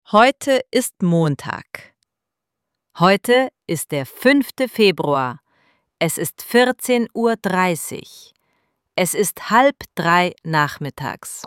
IZGOVOR – RJEŠENJA:
ElevenLabs_Text_to_Speech_audio-52.mp3